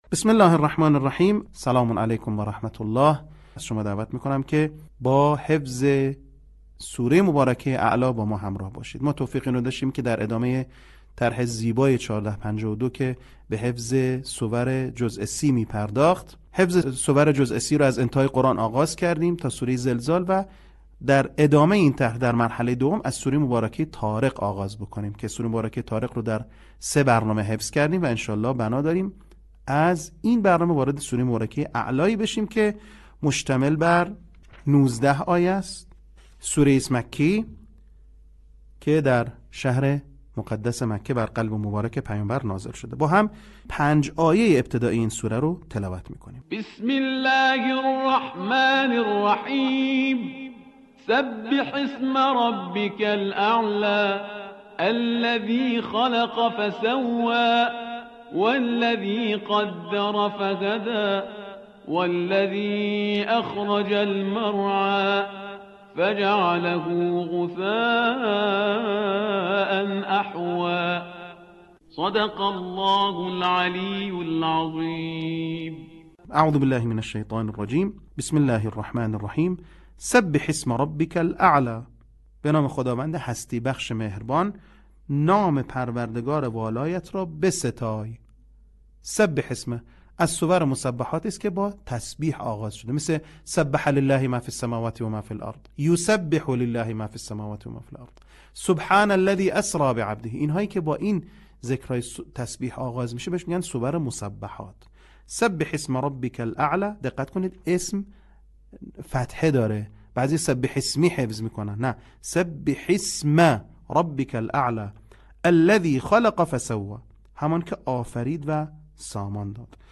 صوت | آموزش حفظ سوره اعلی
به همین منظور مجموعه آموزشی شنیداری (صوتی) قرآنی را گردآوری و برای علاقه‌مندان بازنشر می‌کند.